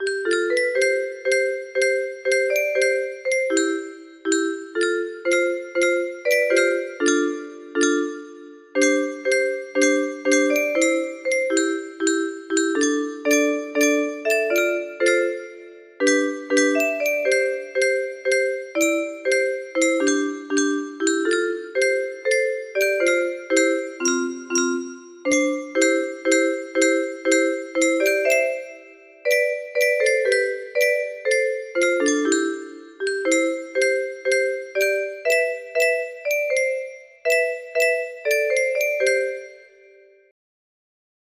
333 music box melody